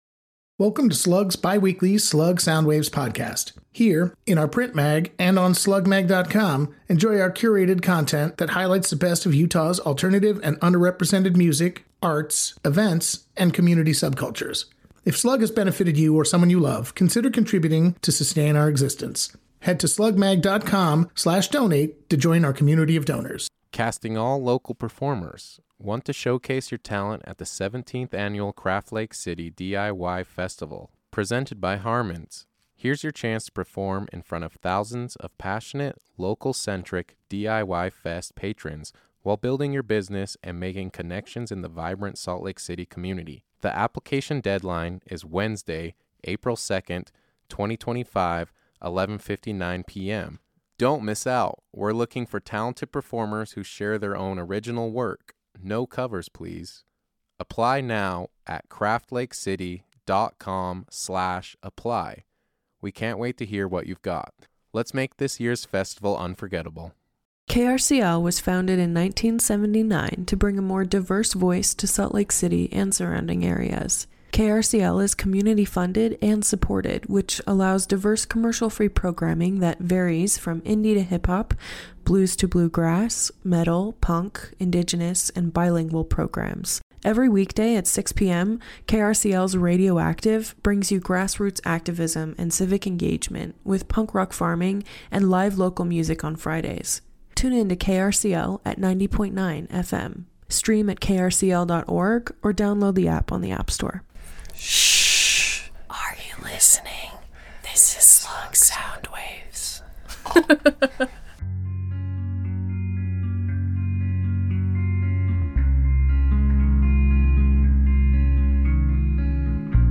an SLC indie-rock project
guitar, vocals
drums